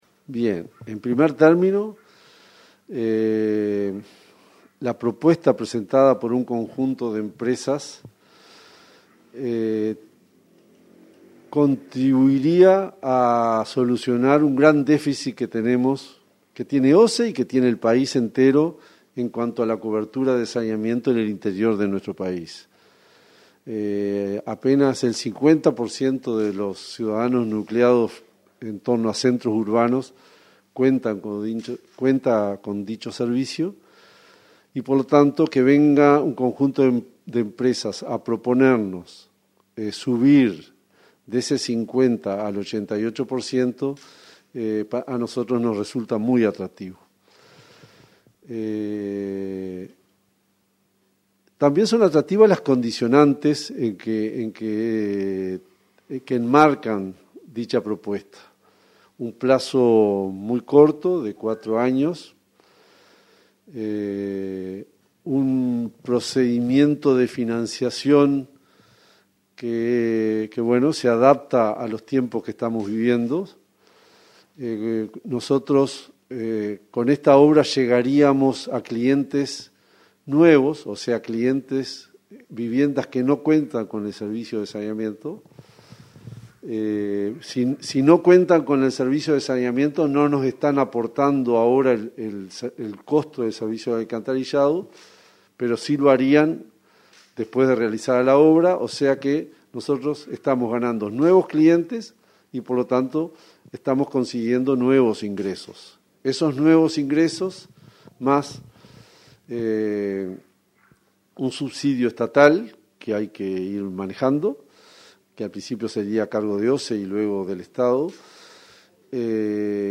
Entrevista al presidente de OSE, Raúl Montero